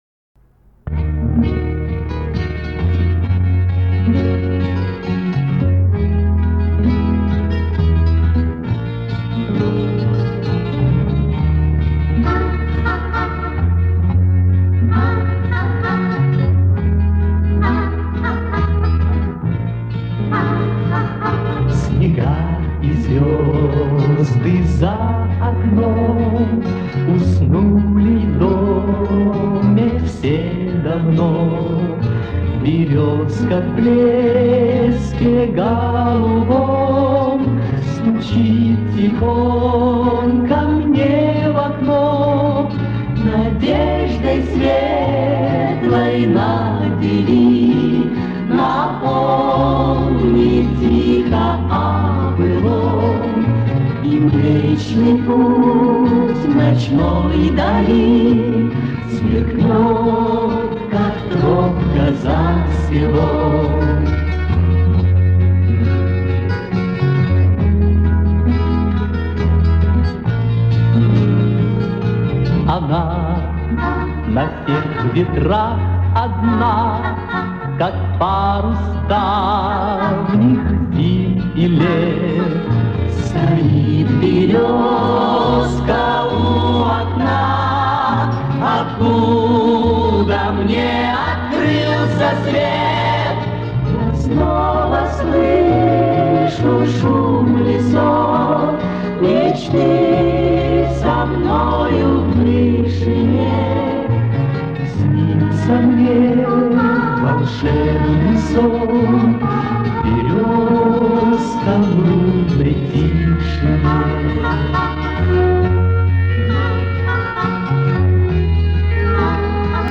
Качество,какое есть